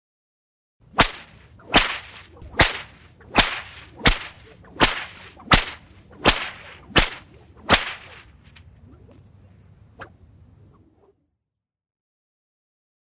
Âm thanh tiếng Quất mạnh bằng Roi